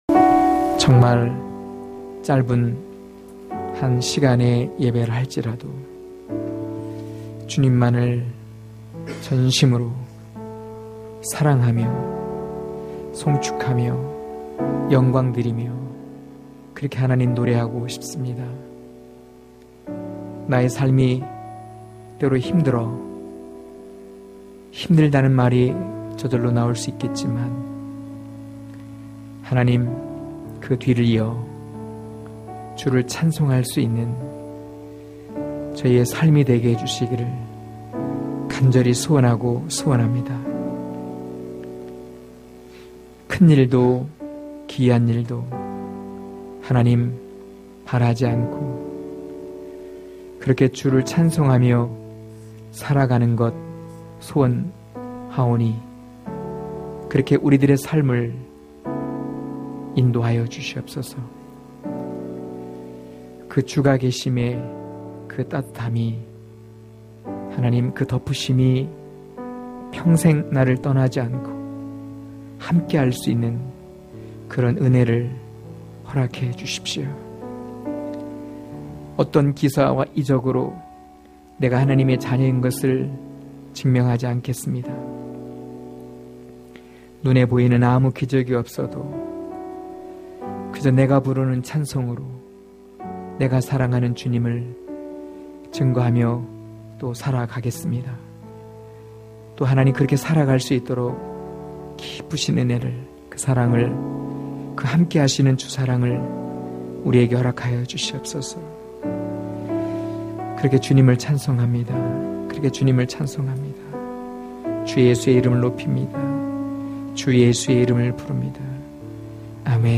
강해설교 - 10.초대 성도님들이 열어 놓은 길(요일5장4-12절)